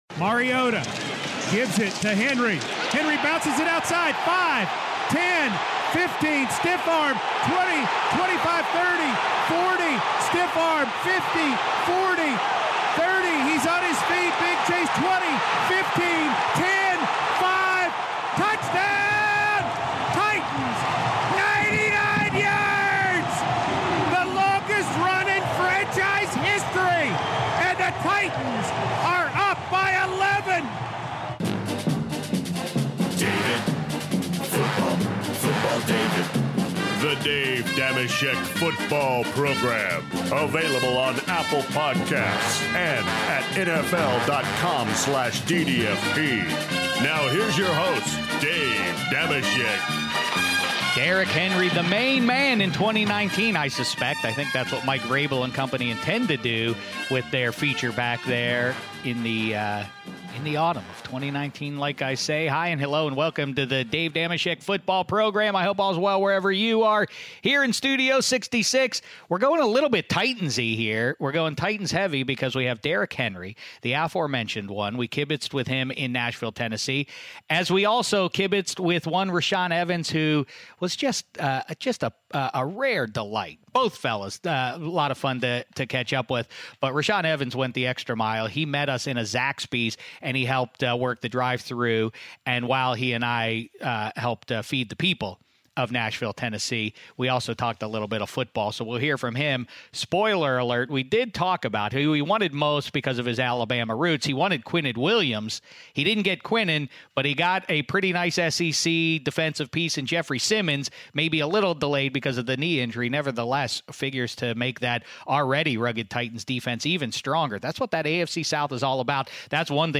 Next up, while at a Zaxby's in Nashville, we hear Shek work the drive-thru with Tennessee Titans linebacker Rashaan Evans (22:00). Then the show rounds out with Shek's conversation with Tennesee Titans running back, Derrick Henry, who kibitzed with Shek while he was in Nashville (34:09).